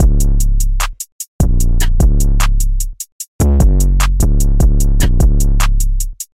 尼克米拉风格的鼓声循环和低音2
描述：不同的打击乐器，更高的低音音符 :)
Tag: 150 bpm Trap Loops Drum Loops 1.08 MB wav Key : C